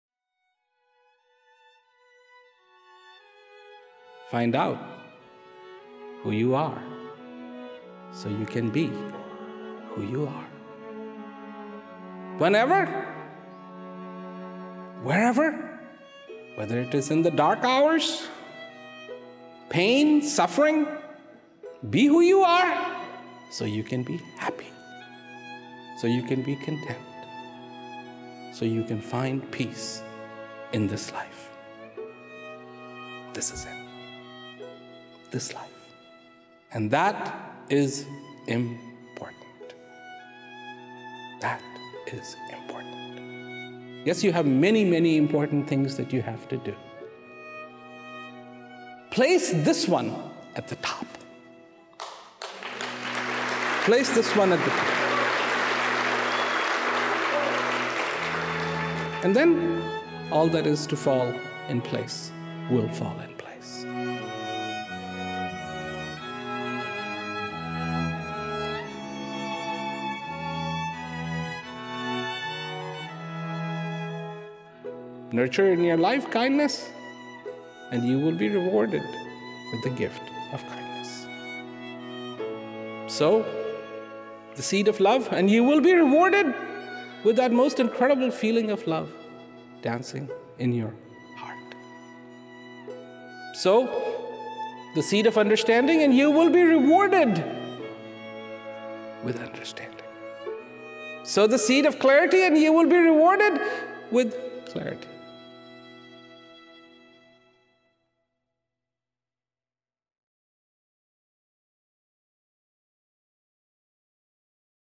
A string quartet plays as Prem Rawat emotes Track 19.